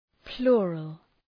{‘plʋrəl}